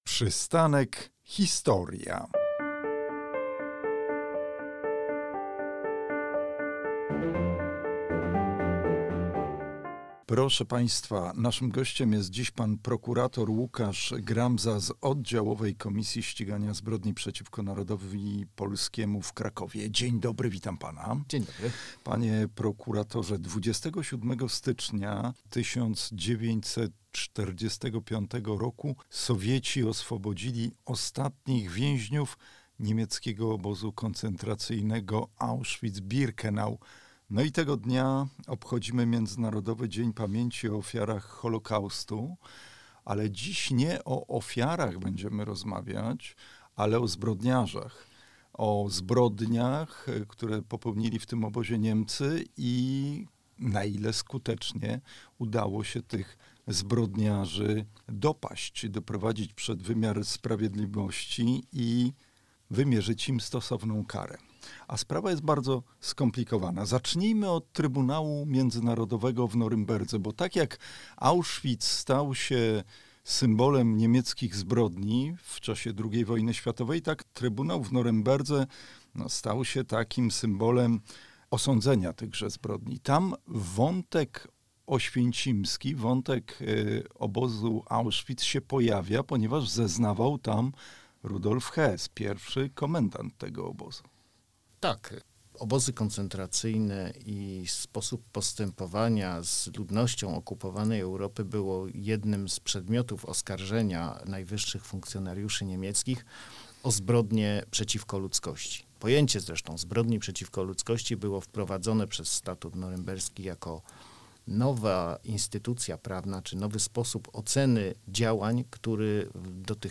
Zbrodniarze Auschwitz. Rozmowa